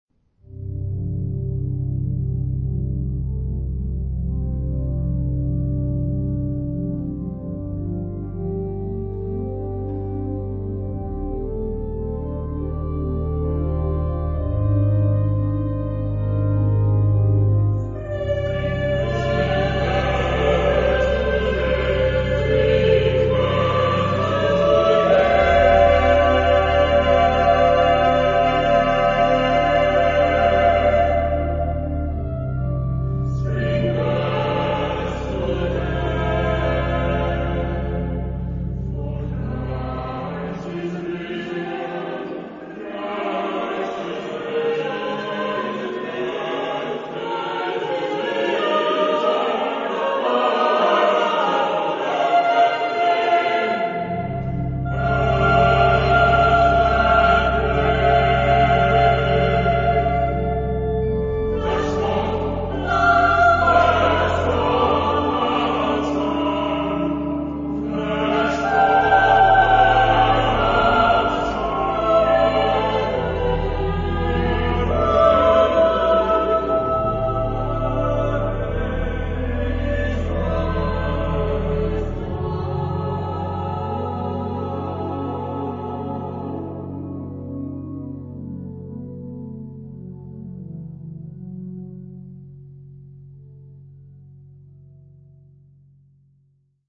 Genre-Style-Form: Sacred ; Choir
Type of Choir: SSAATTBB  (8 mixed voices )
Instrumentation: Organ  (1 instrumental part(s))
Tonality: F major